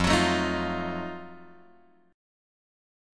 F7b9 chord {1 x 4 2 4 1} chord